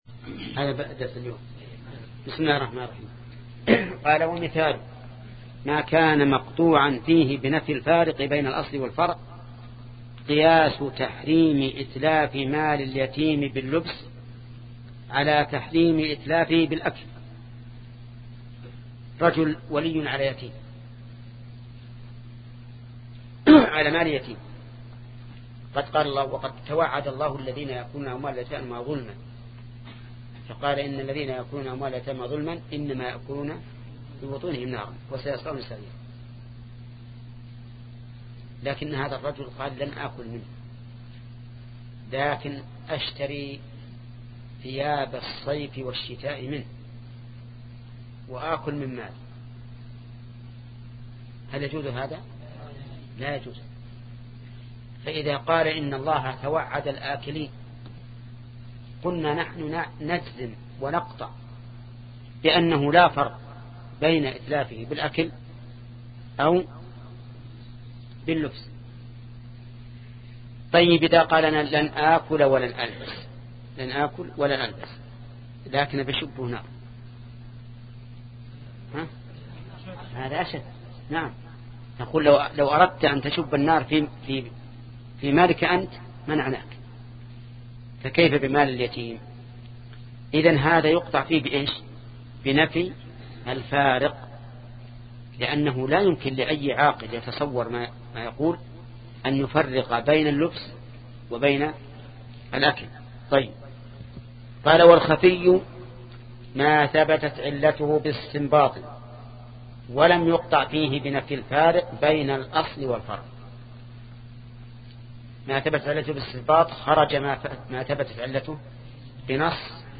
شبكة المعرفة الإسلامية | الدروس | الأصول من علم الأصول 19 |محمد بن صالح العثيمين